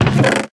sounds / block / chest / open.ogg